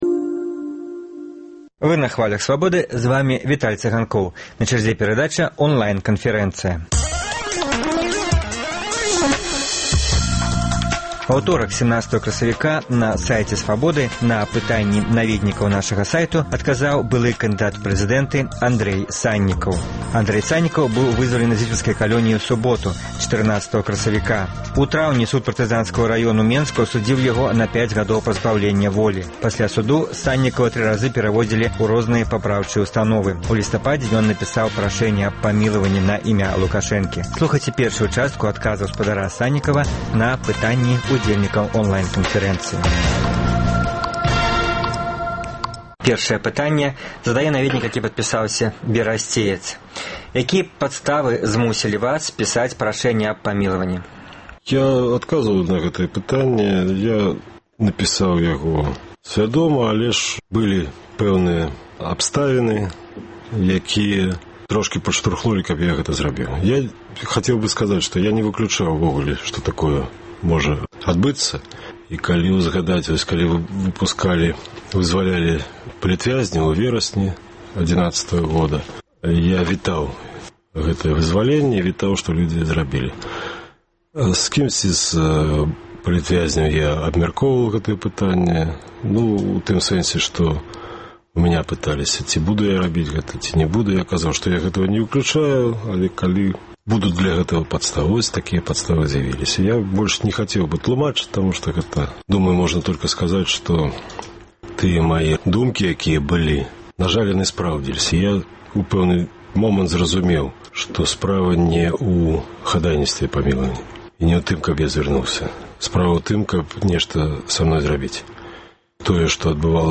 Радыёварыянт онлайн-канфэрэнцыі з былым кандыдатам у прэзыдэнты Андрэем Саньнікавым.